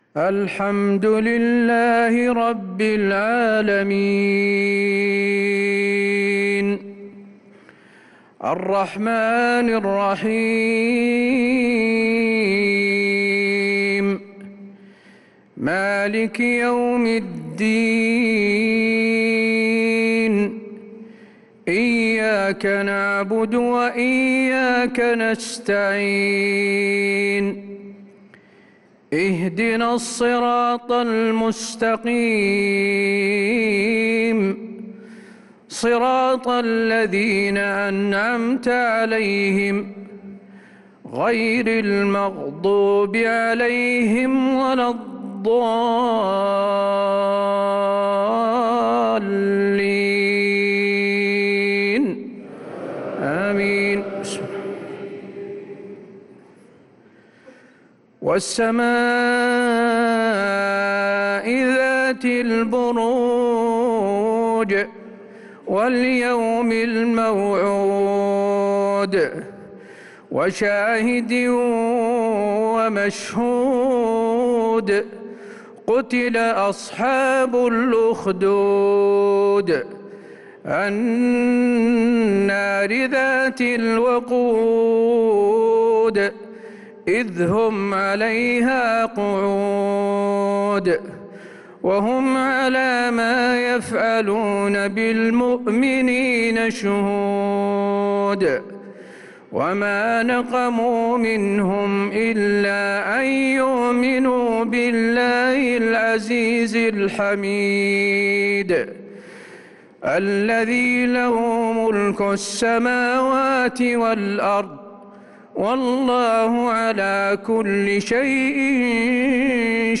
عشاء الجمعة 7-9-1446هـ سورة البروج كاملة | Isha prayer Surat al-Buruj 7-3-2025 > 1446 🕌 > الفروض - تلاوات الحرمين